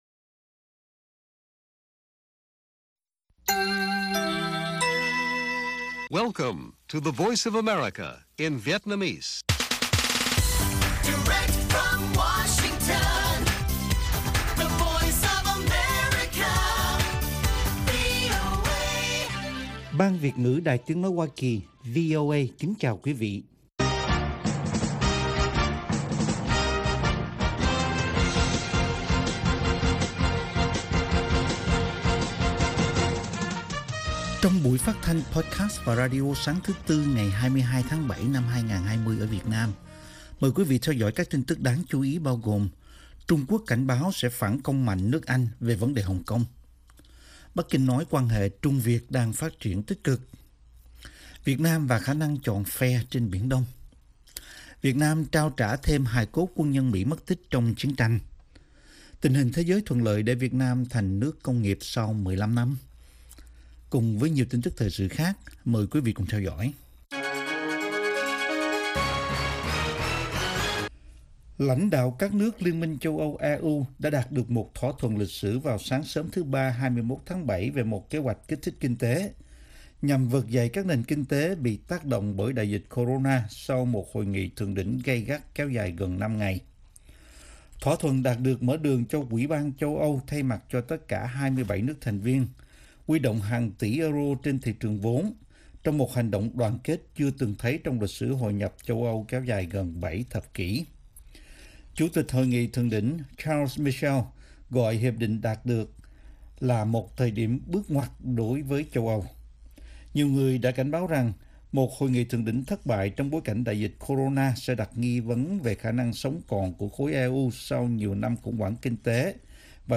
Bản tin VOA ngày 22/7/2020